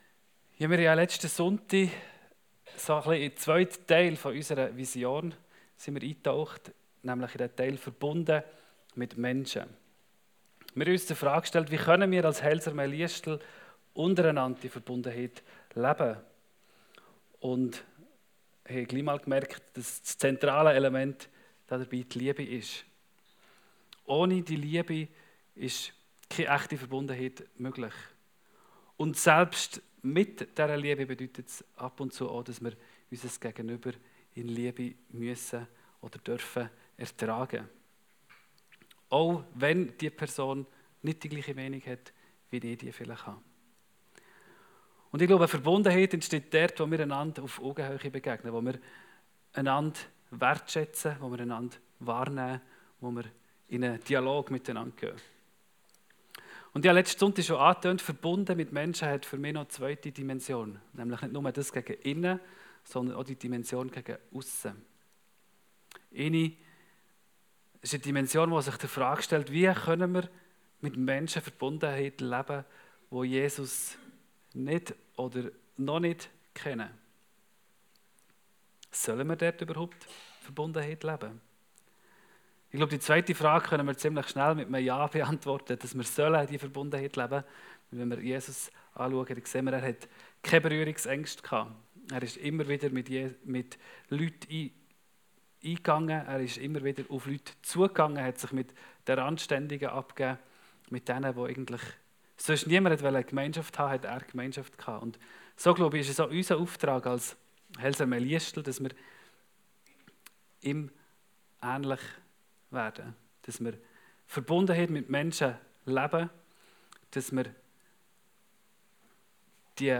Predigtserie zur Vision der Heilsarmee Liestal